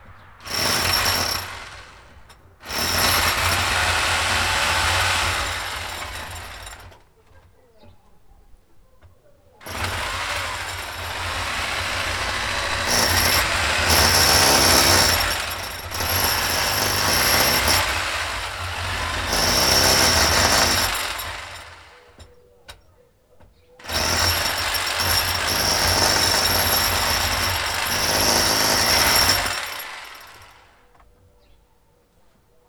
• power drill drilling in concrete outdoor.wav
Recorded outdoor in open field with a Tascam DR 40 linear PCM recorder, while drilling from within a concrete basement.
power_drill_drilling_in_concrete_outdoor_ISy.wav